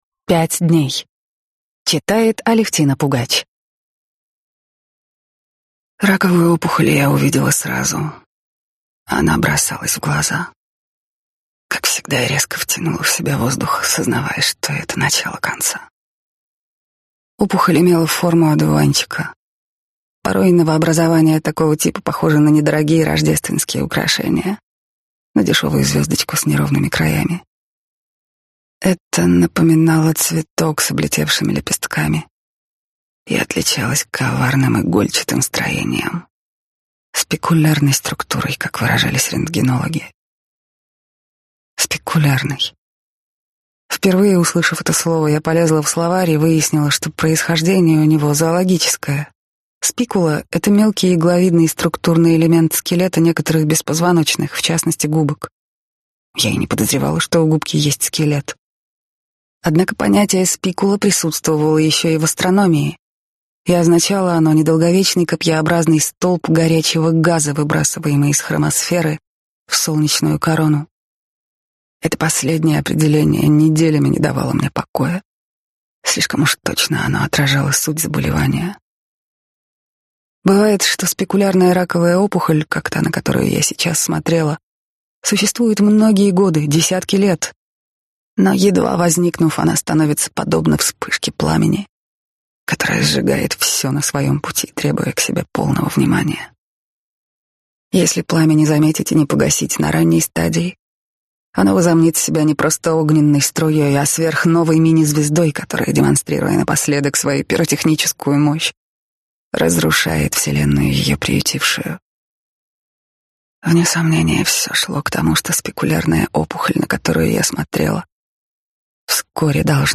Аудиокнига Пять дней | Библиотека аудиокниг